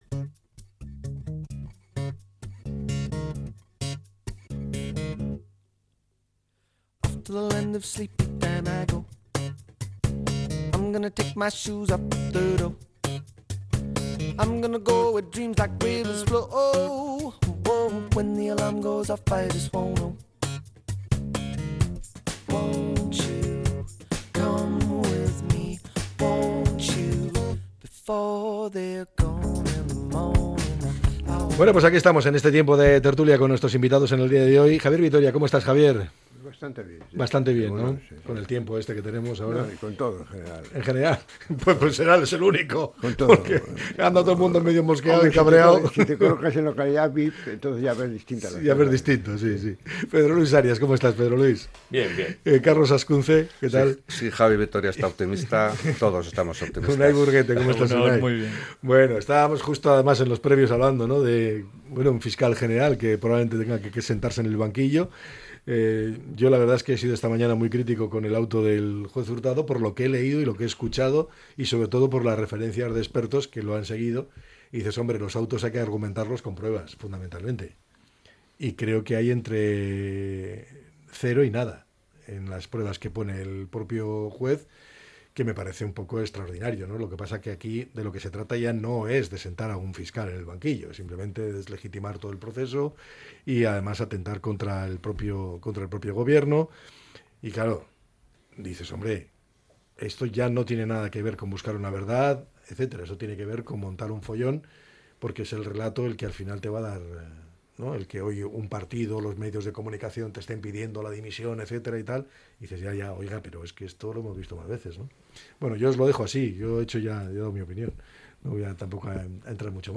La tertulia 10-06-25.